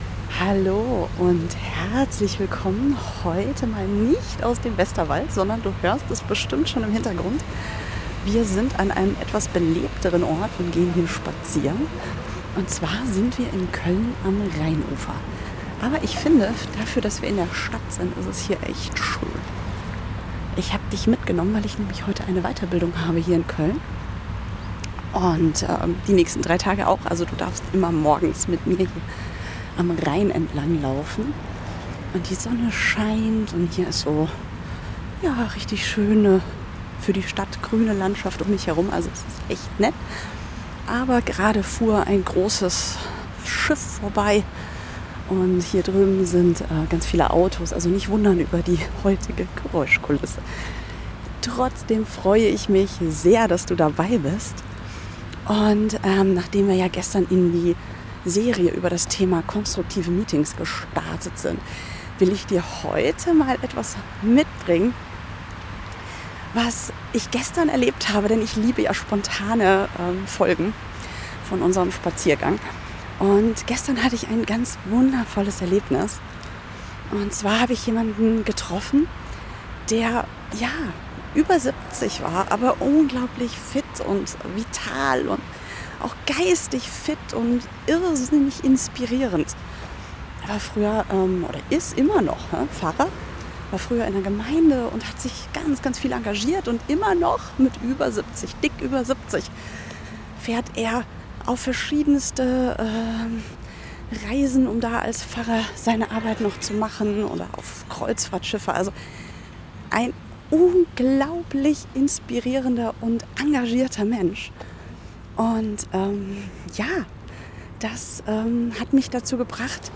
Spaziergang 18.
bin heute mal spontan in Köln am Rhein unterwegs und hatte gestern